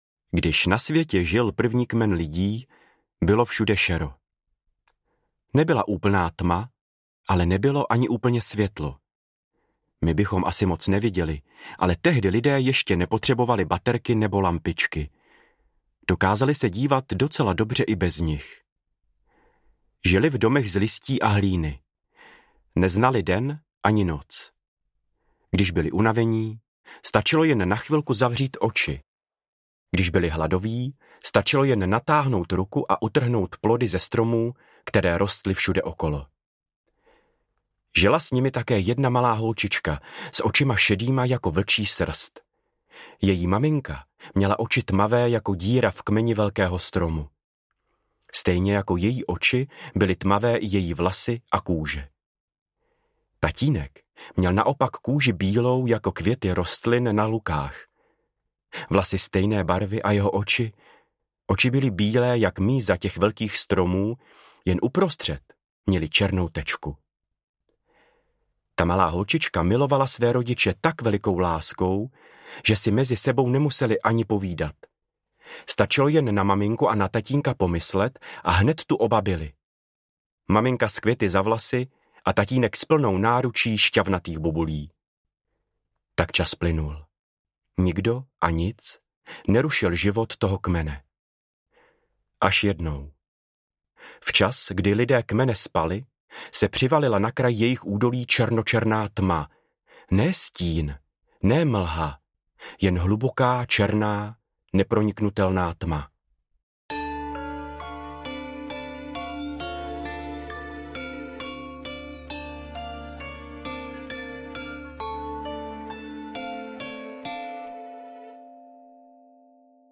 Audiobook
Read: Ester Geislerová